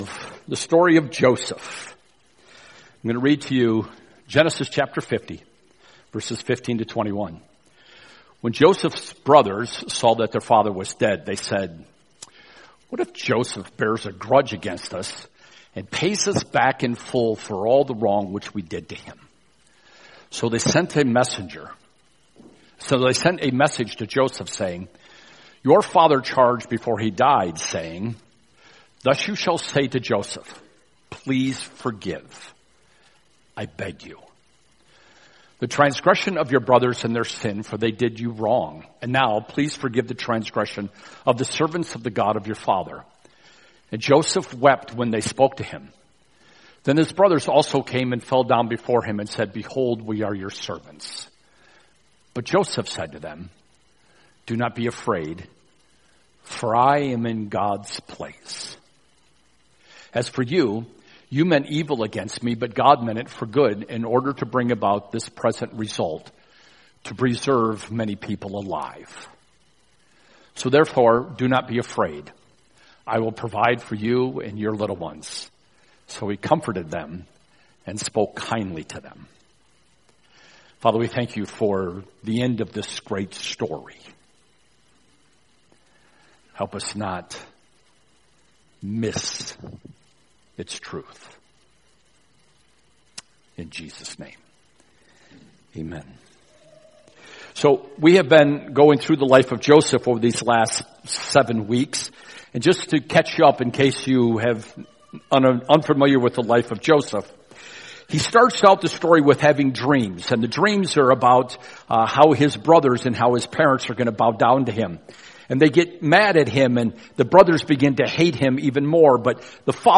SUNDAY'S SERMON